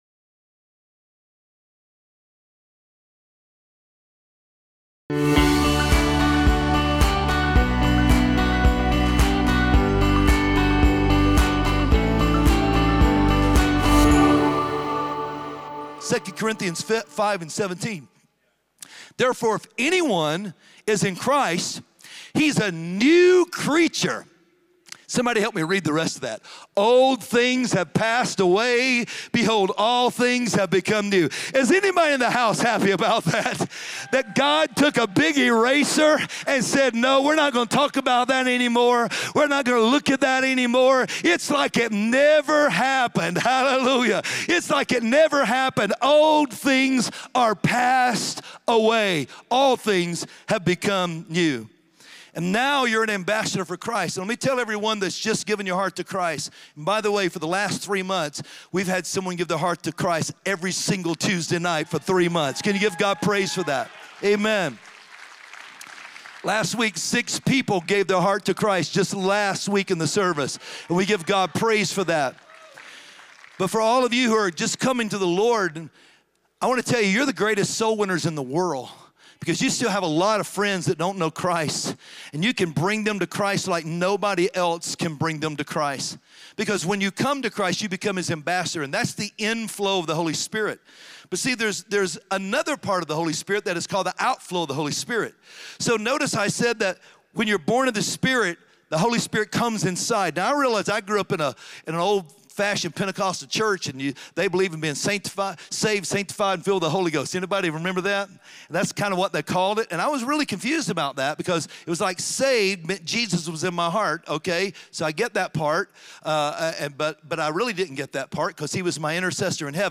Join us this week for the sermon “All Things Become New!”